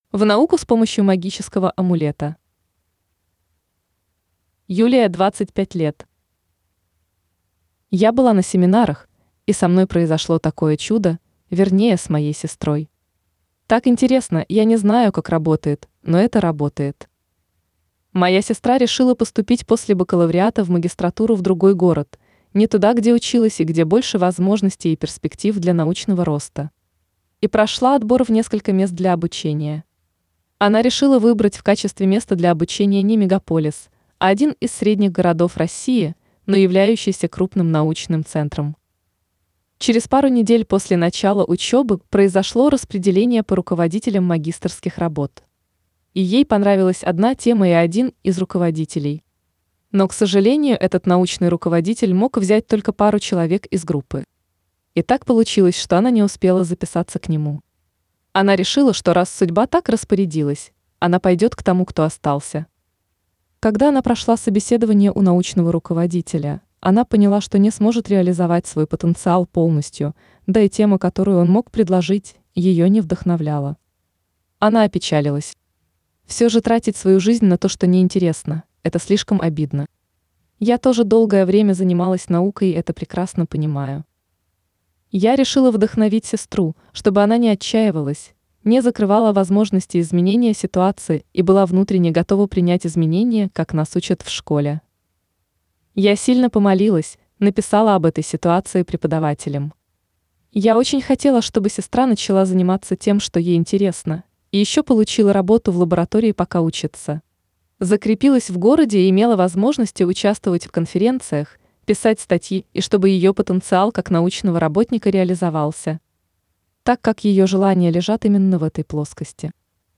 Жанр: Аудио книга.